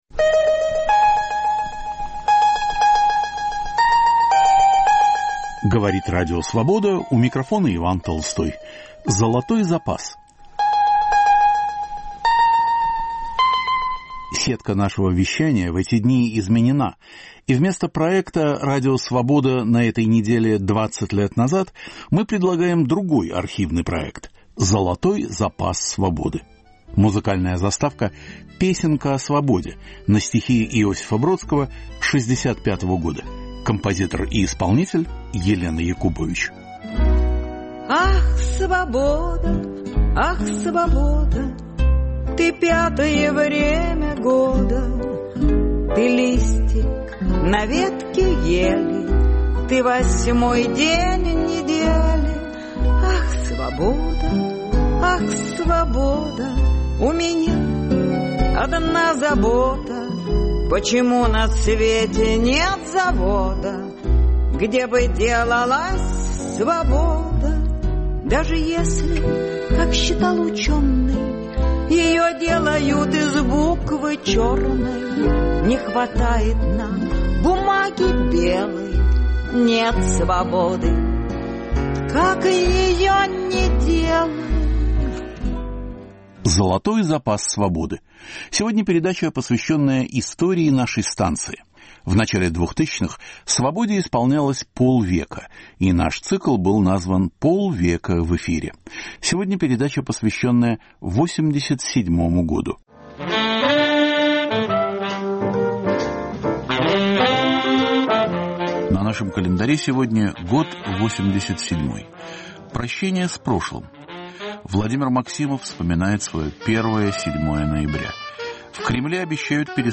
К 50-летию Радио Свобода.
Сергей Довлатов о новых публикациях в СССР. Дискуссия о Сталине.